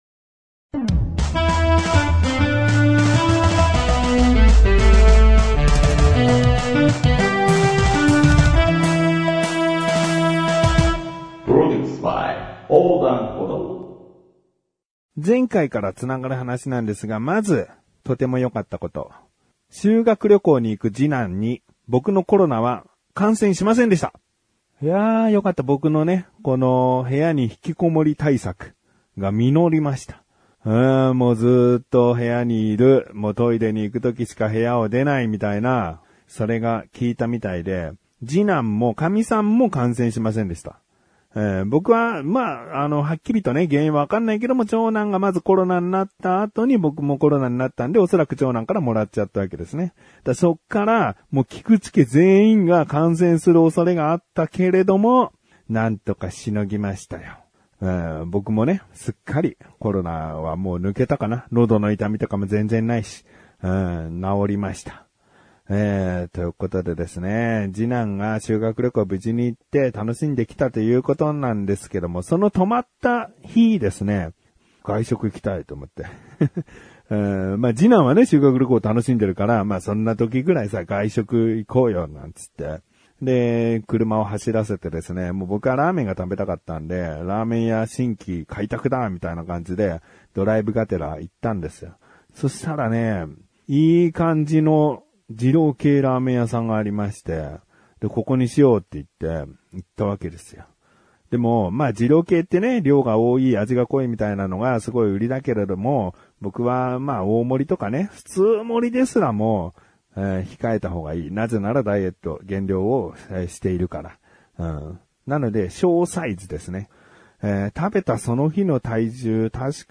… continue reading 934 episodi # コメディ # トーク # ネットラジオ # Ｏｄｅａｎｄ舗堂